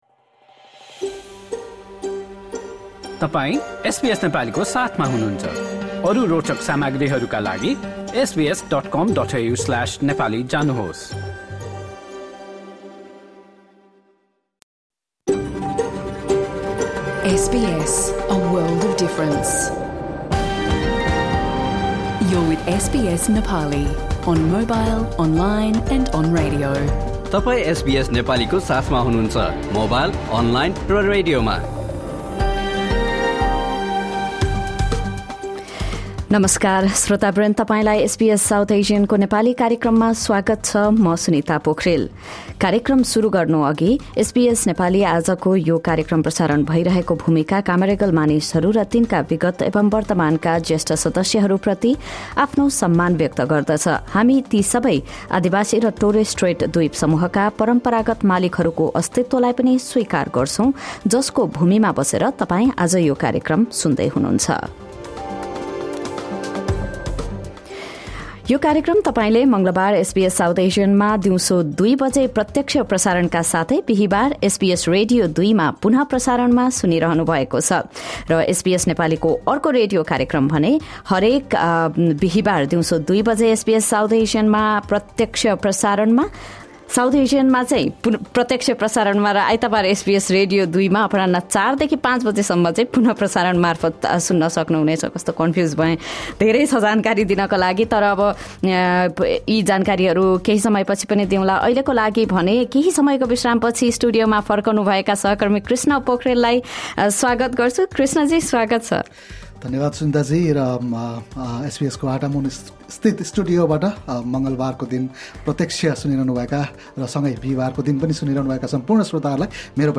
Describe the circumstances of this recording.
SBS Nepali broadcasts a radio program every Tuesday and Thursday at 2 PM on SBS South Asian digital radio and channel 305 on your TV, live from our studios in Sydney and Melbourne.